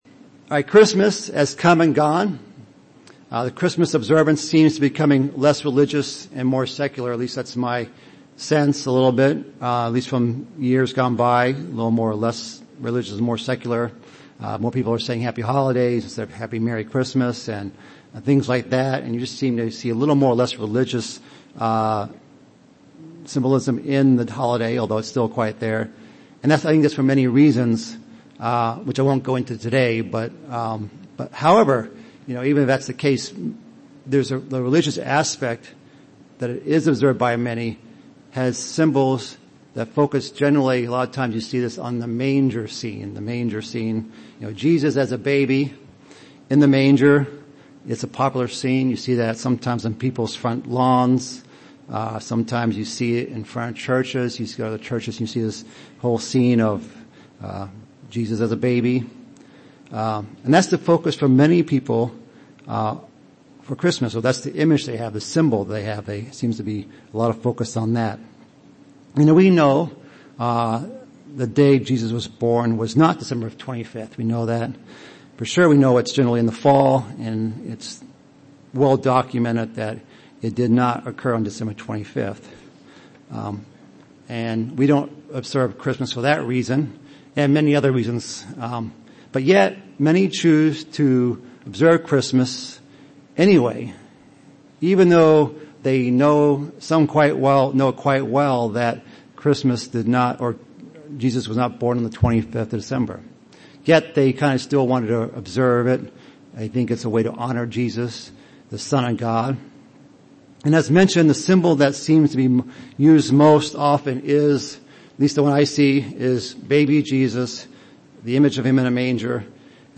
Given in Chicago, IL
UCG Sermon Studying the bible?